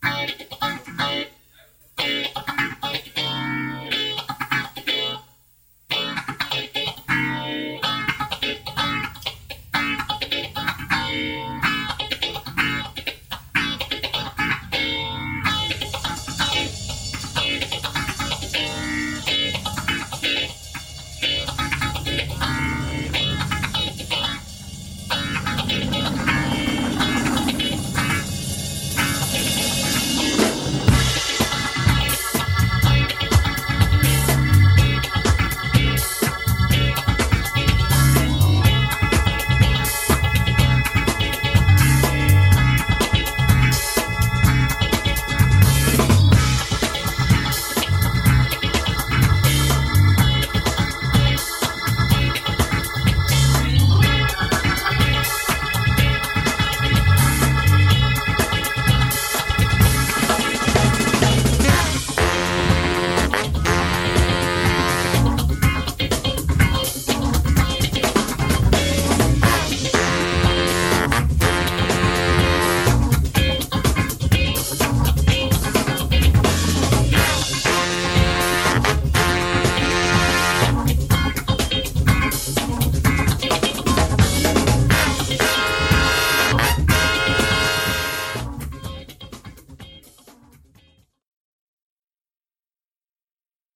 ジャンル(スタイル) SOUL / FUNK / DISCO